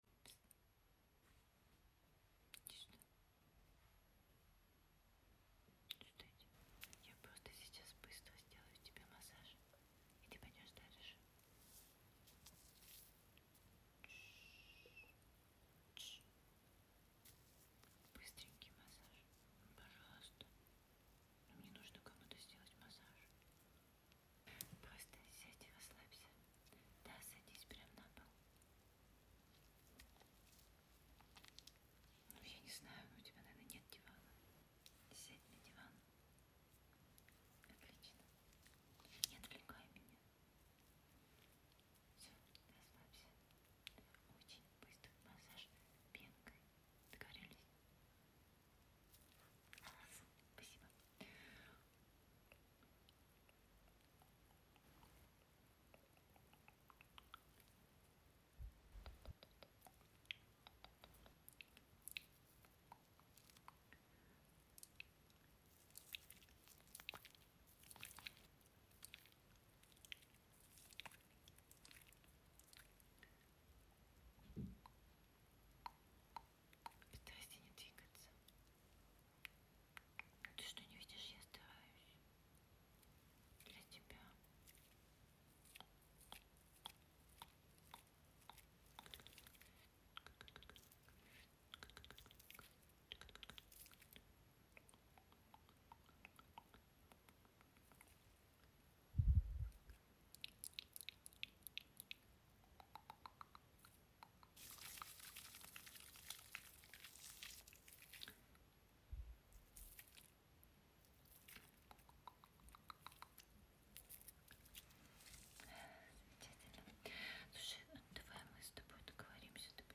ASMR, but I want to sound effects free download